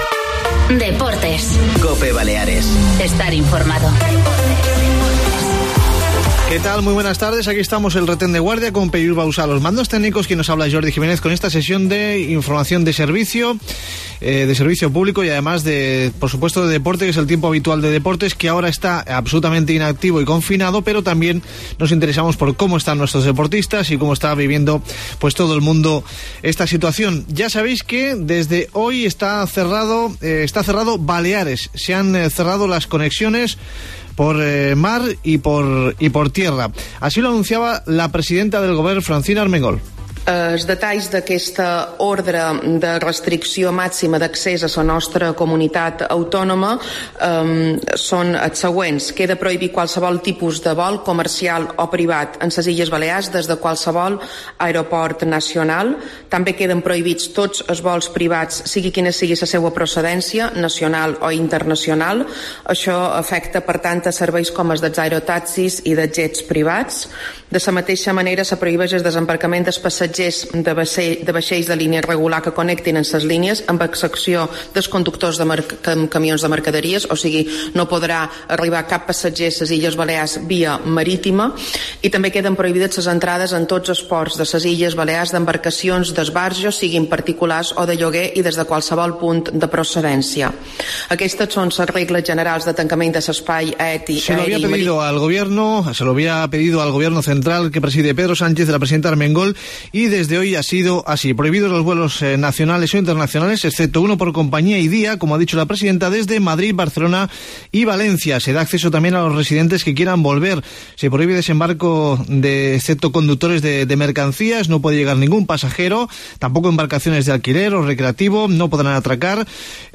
Crónica de cosas que han pasado, la principal el cierre de Baleares por aire y mar, salvo un vuelo por día y compañía desde Madrid, Barcelona y Valencia, igualmente reducida al mínimo entre islas y no llegan pasajeros en barco, sólo mercancías. Escuchamos a la presidenta Armengol.
Y más sonidos del día.